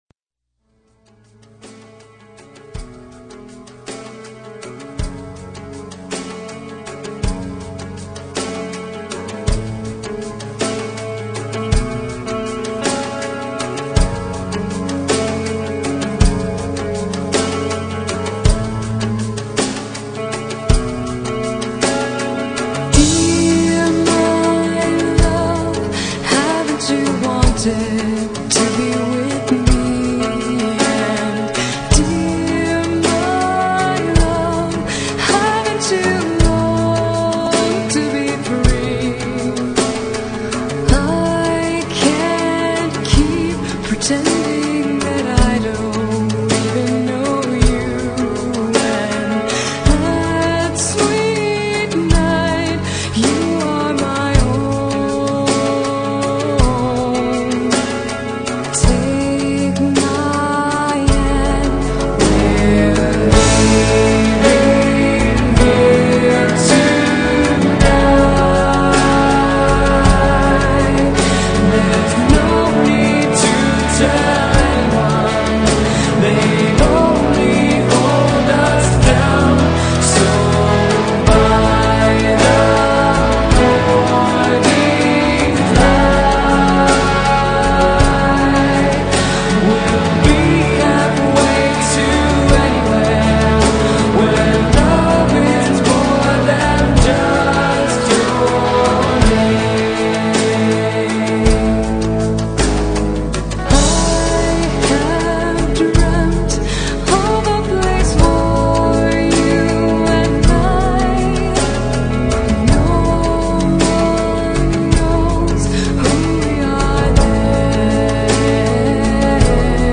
backup vocals